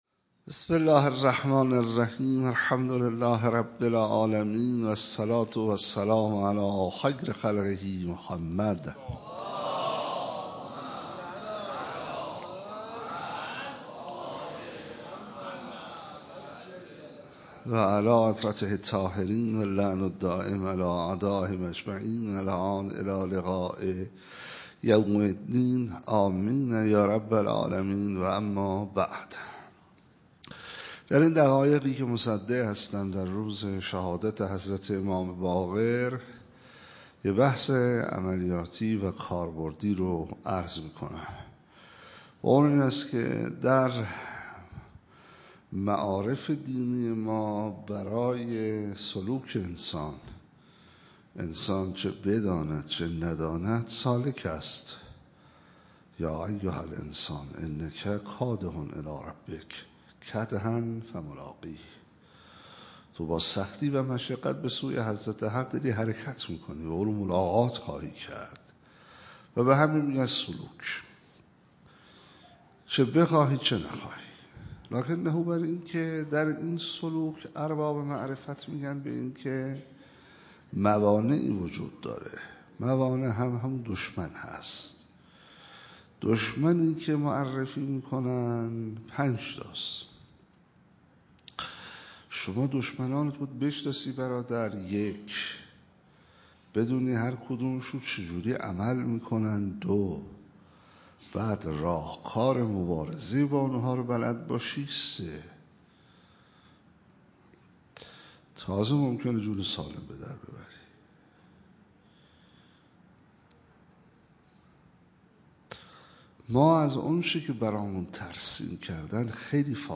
28 مرداد 97 - حسینیه حق شناس - سخنرانی